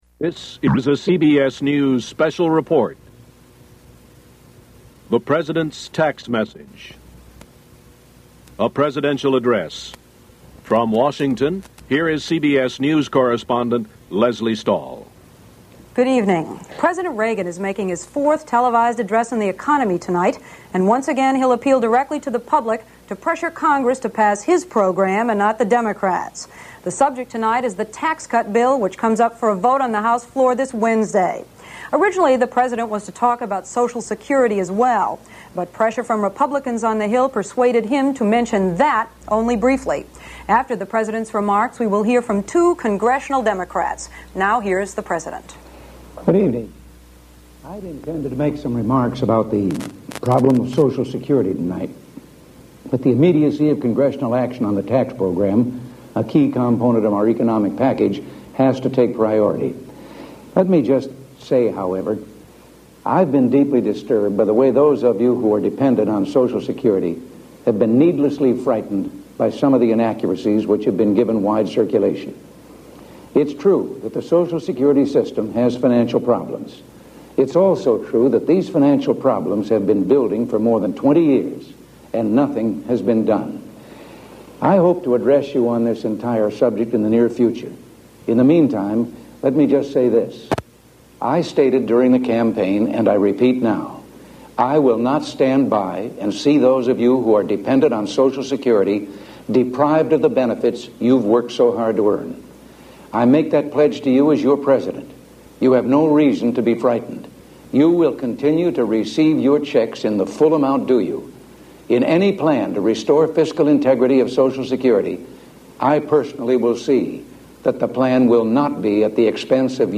U.S. President Ronald Reagan's fourth television address to the nation. He speaks briefly about Social Security and then about the economy. Recording begins with an introduction by reporter Lesley Stahl.